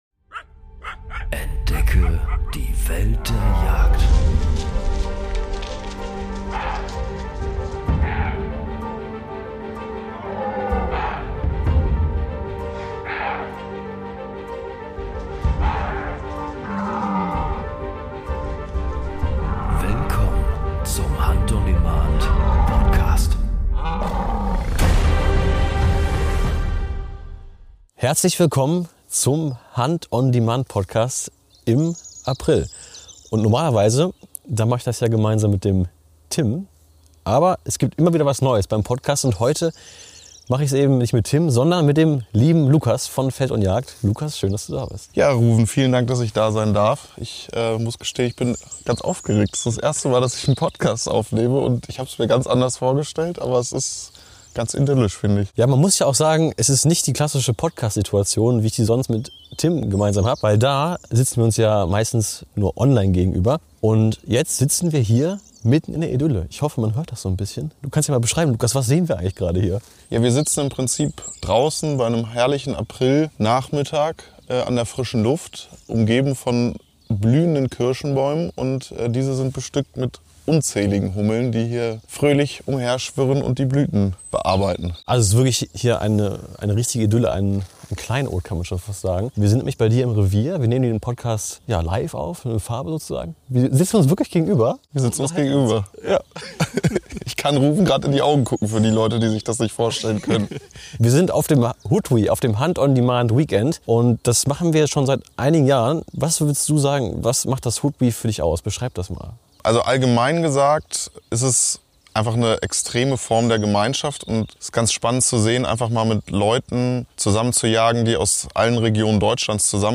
Direkt vom HODWE in Brandenburg: Die Vögel zwitschern, die Hummeln summen